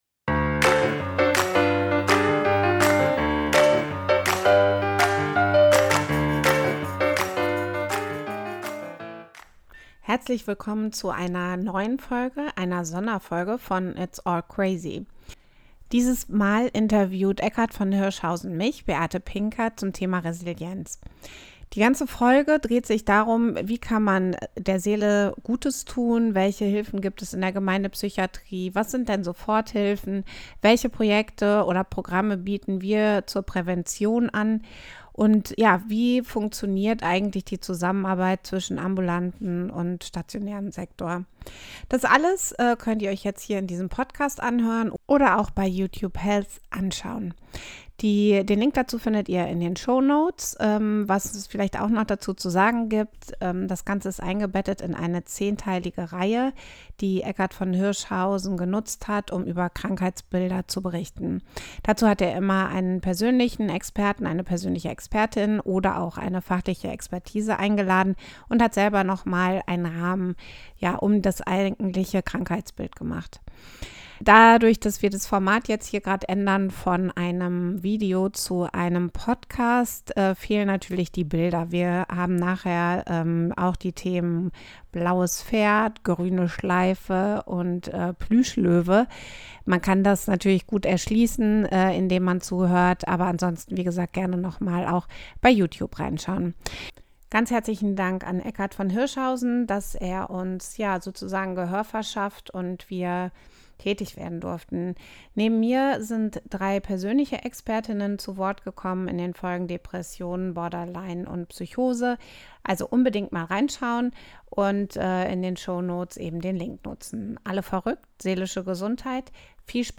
Im Interview mit Eckart von Hirschhausen und dieses Mal mit umgekehrten Rollen.